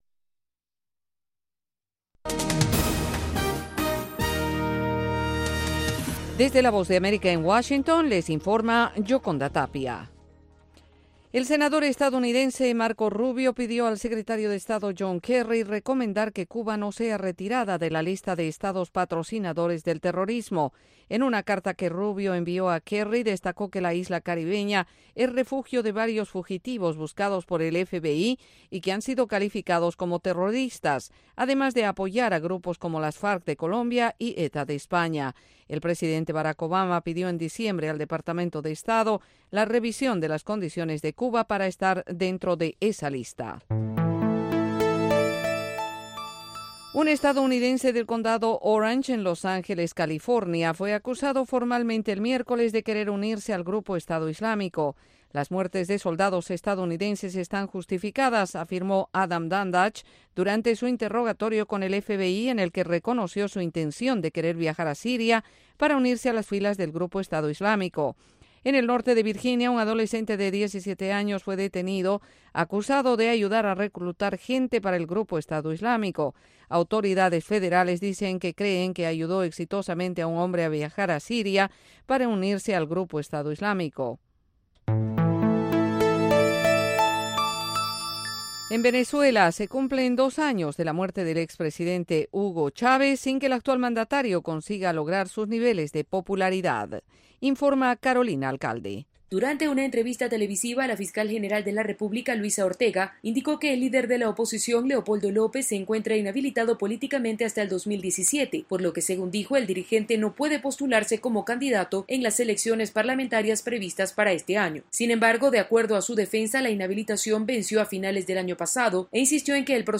Informativo VOASAT